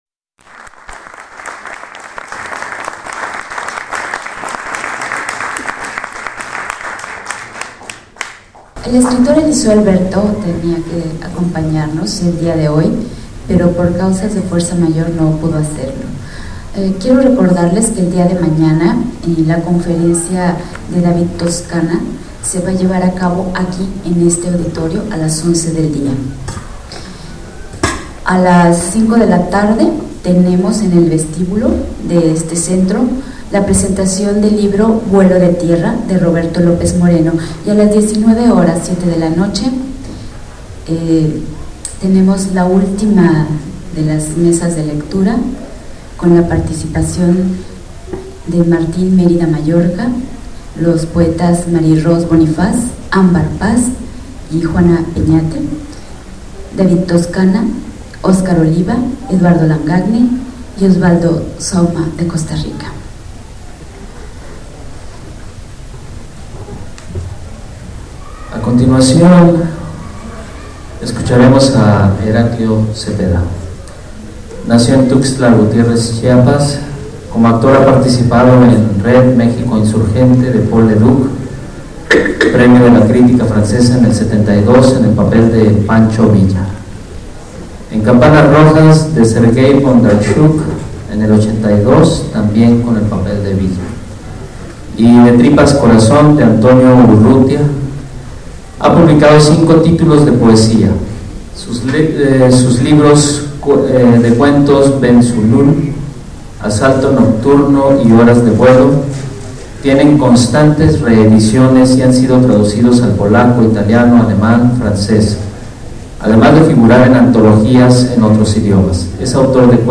Lugar: Teatro Daniel Zebadúa de San Cristóbal de Las Casas, Chiapas.
Equipo: iPod 2Gb con iTalk Fecha: 2008-11-06 13:12:00 Regresar al índice principal | Acerca de Archivosonoro